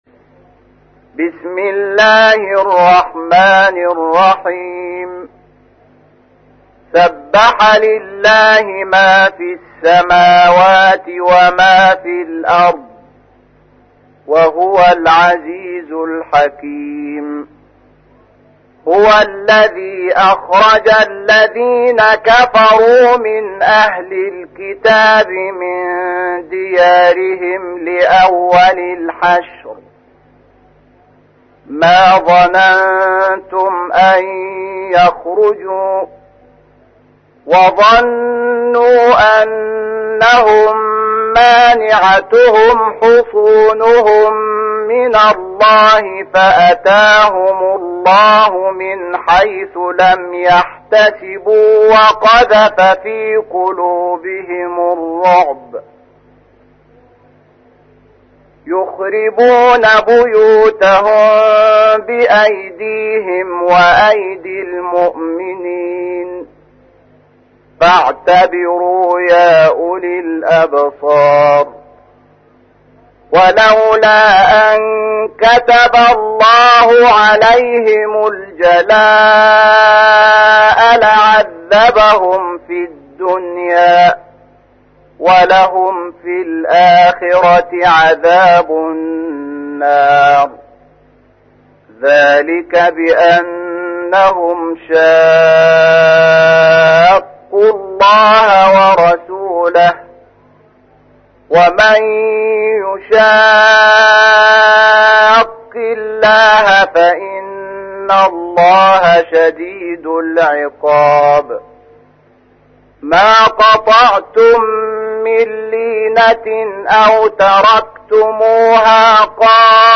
تحميل : 59. سورة الحشر / القارئ شحات محمد انور / القرآن الكريم / موقع يا حسين